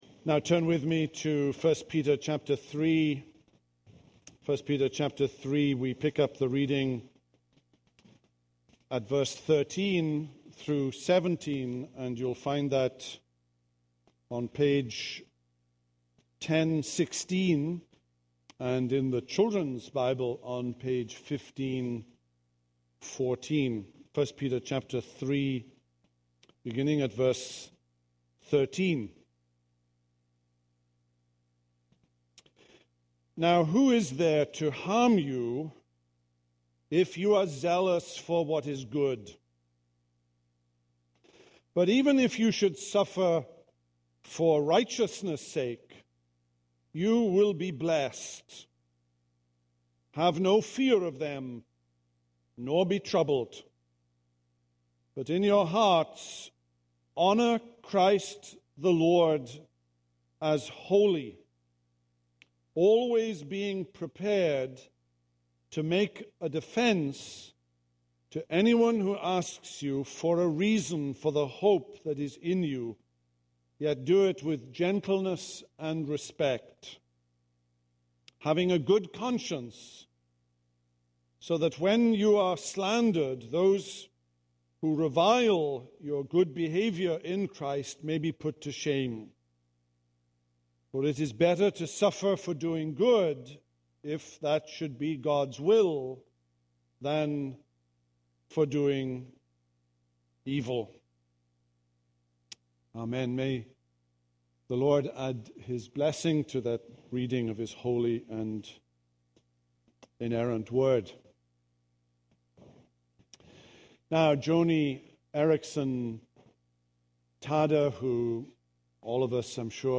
This is a sermon on 1 Peter 3:13-17.